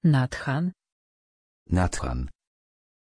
Pronunciation of Nathan
pronunciation-nathan-pl.mp3